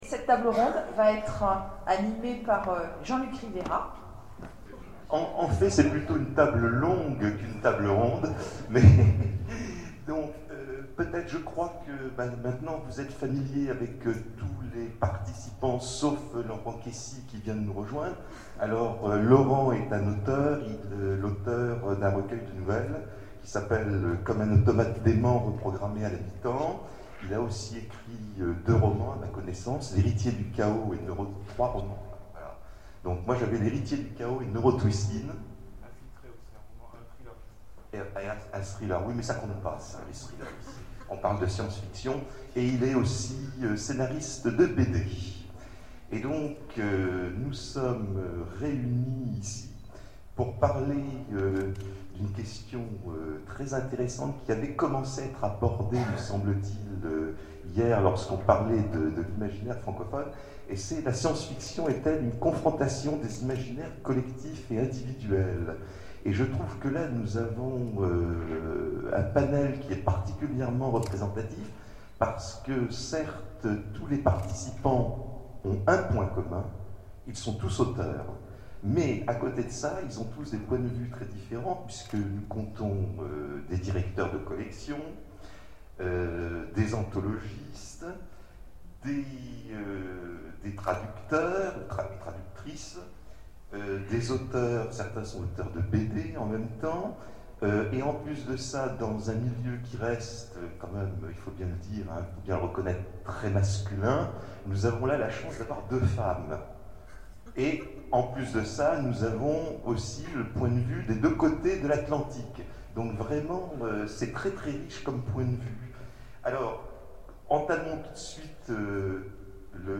Colloque SF francophone : Table ronde La science-fiction, confrontation des imaginaires collectifs ou individuels ?
Conférence